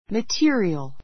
mətí(ə)riəl